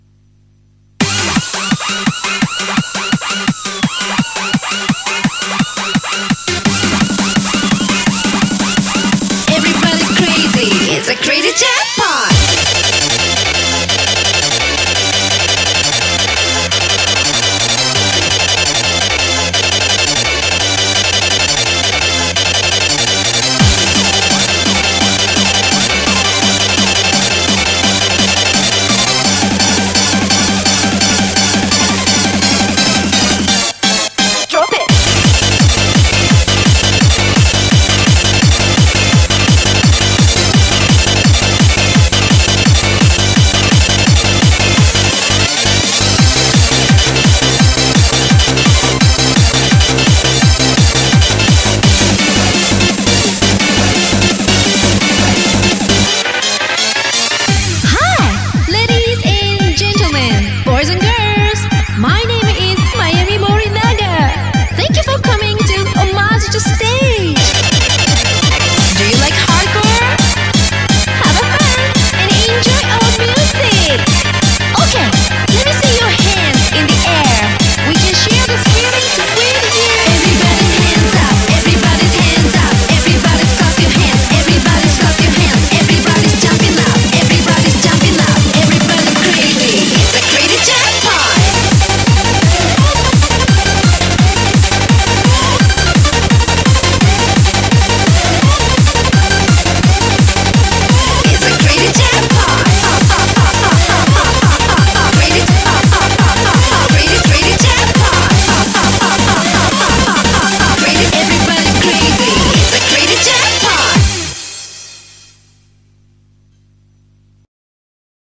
BPM170
Audio QualityPerfect (High Quality)
Comentarios[RAVE]